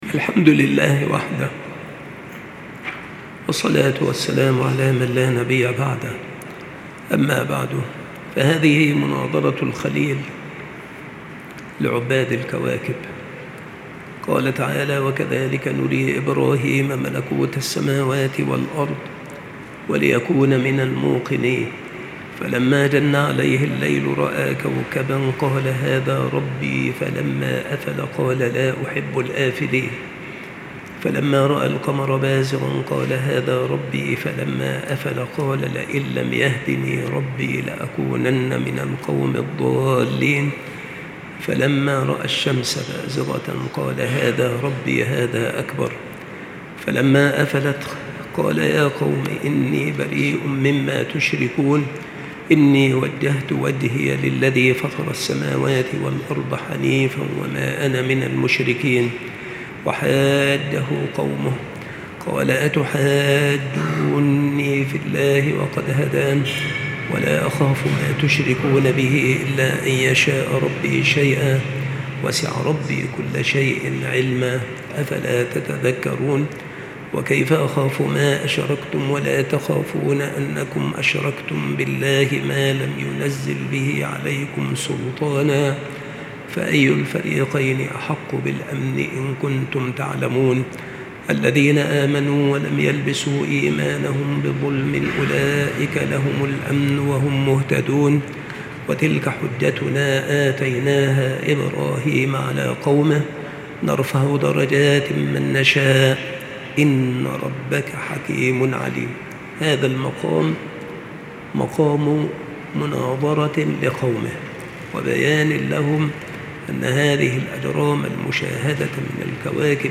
التصنيف : قصص الأنبياء
• مكان إلقاء هذه المحاضرة : بالمسجد الشرقي - سبك الأحد - أشمون - محافظة المنوفية - مصر